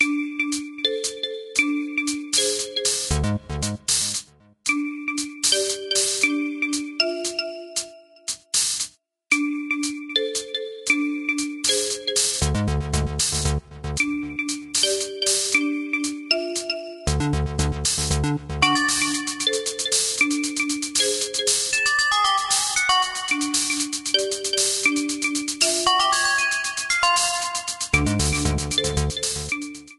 trimmed & added fadeout You cannot overwrite this file.